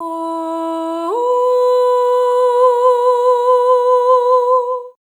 SOP5TH E4B-R.wav